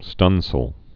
(stŭnsəl, stŭdĭng-sāl)